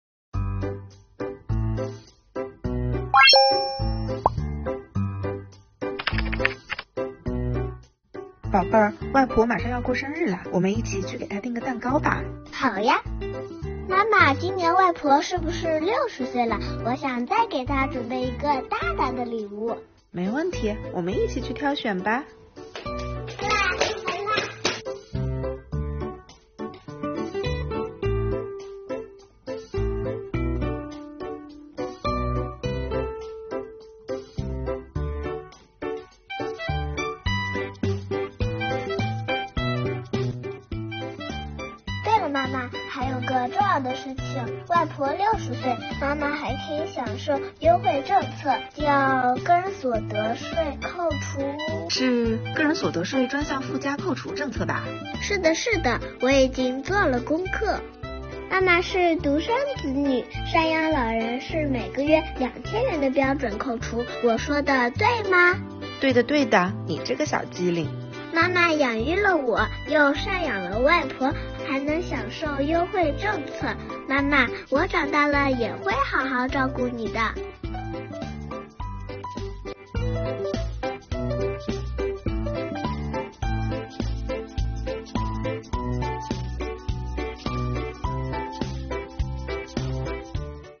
今天，关于个人所得税专项附加扣除的小知识来啦！赡养老人是每个子女应尽的义务，如今还能享受税收优惠政策哦！快听听小朋友们是怎么说的吧！